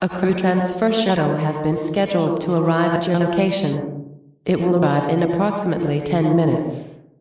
shuttlecalled.ogg